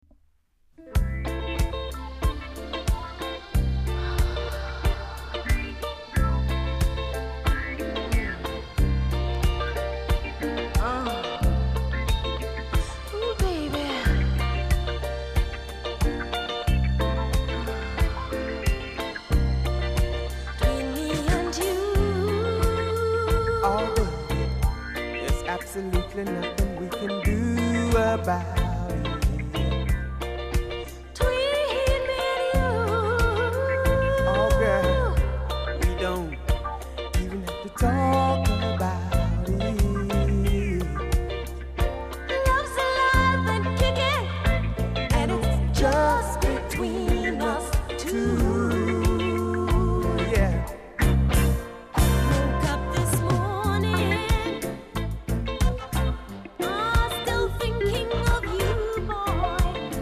※小さなチリノイズが少しあります。
コメント ドリーミーなMELLOW LOVERS!!